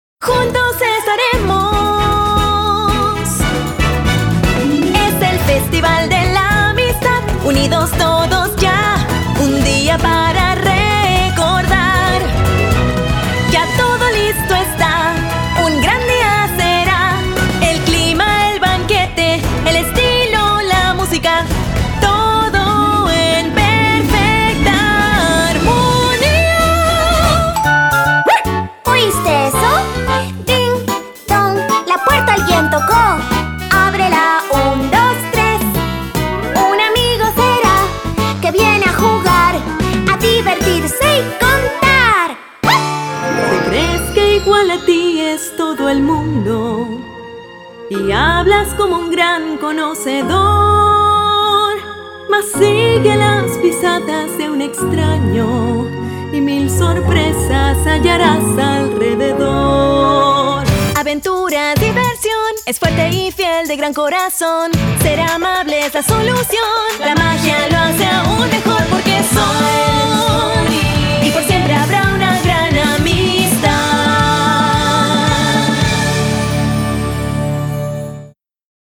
Female
Approachable, Character, Conversational, Corporate, Natural, Warm, Young
narration_English.mp3
Microphone: Manley reference Cardioid